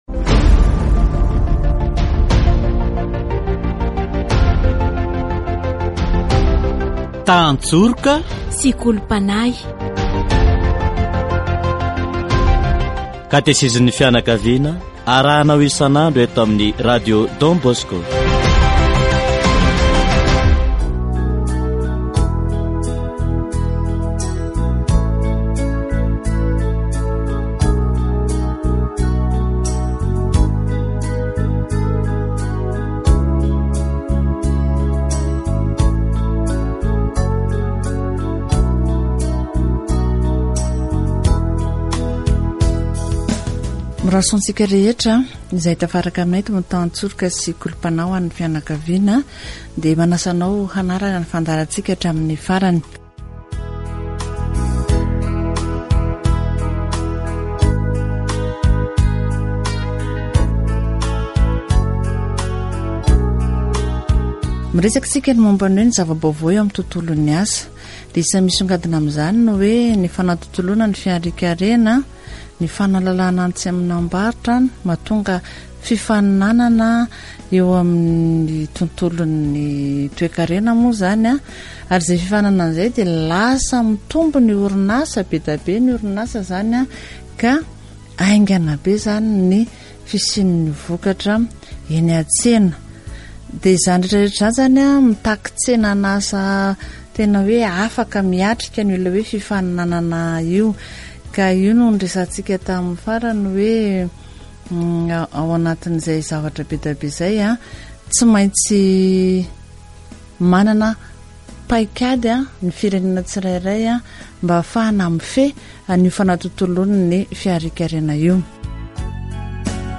Catéchèse sur le travail